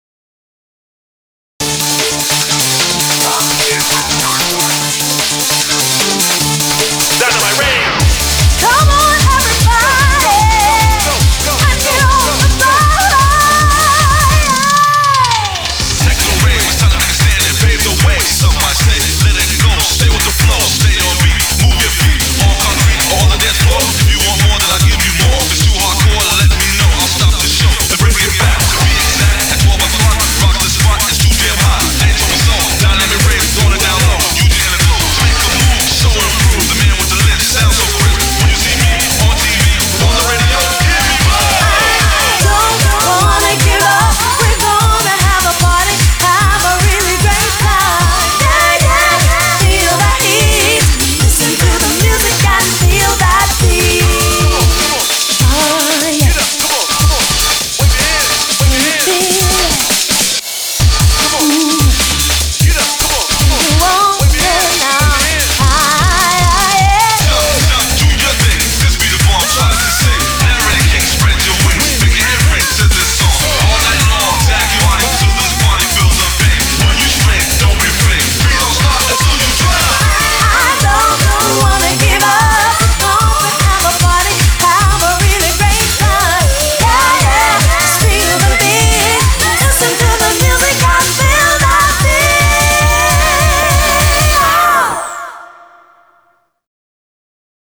BPM 150
Audio Quality Perfect (High Quality)